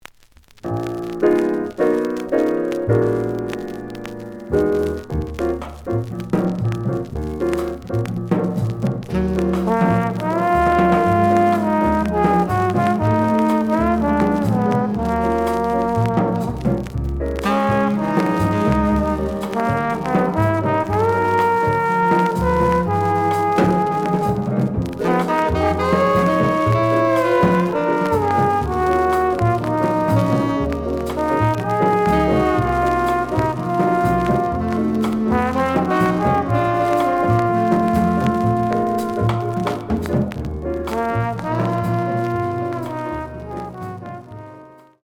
The audio sample is recorded from the actual item.
●Genre: Hard Bop
Slight noise on B side.)